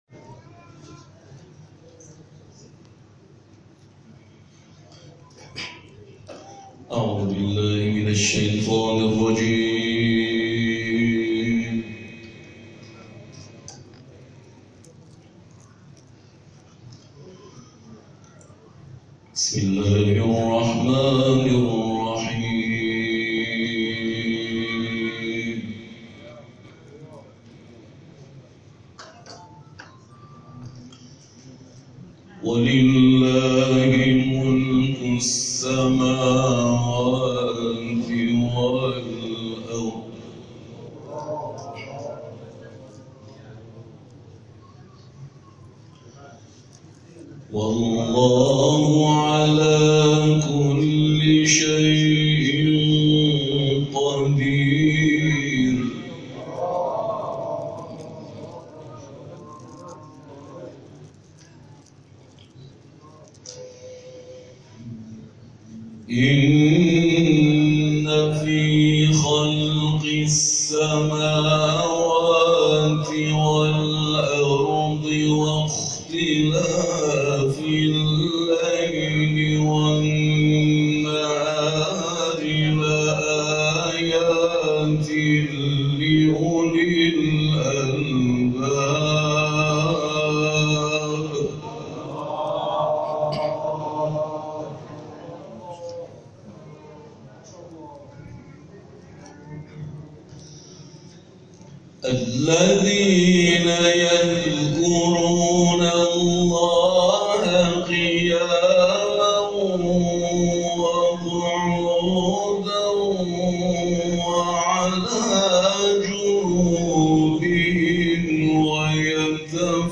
این تلاوت هفته گذشته در محفل انس با قرآن در آستان مقدس امامزاده عیسی(ع) شهرک واوان اسلامشهر اجرا شده است.